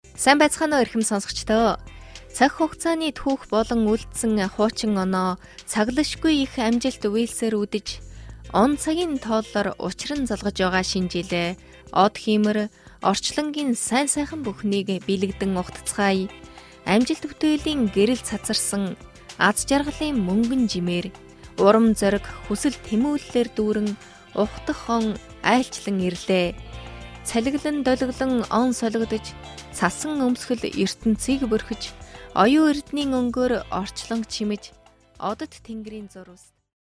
Professionelle Sprecher und Sprecherinnen
Weiblich